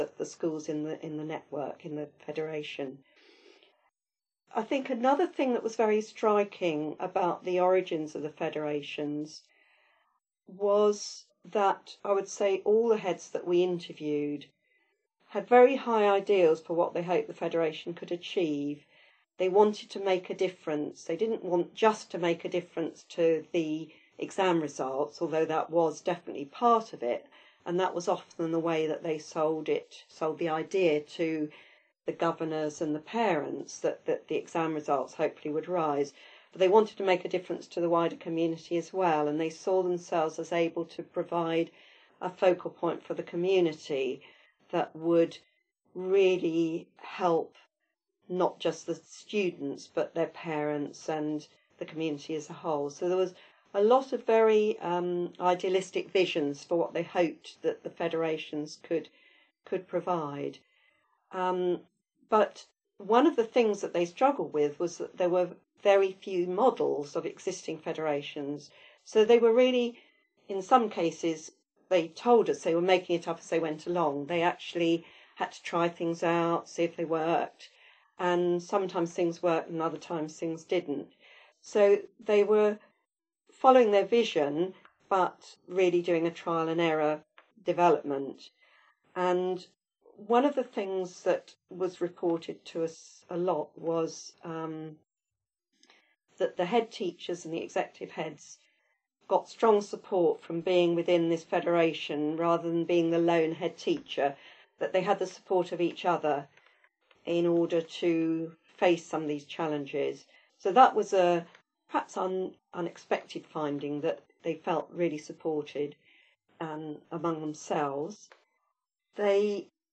interview_selection2.mp3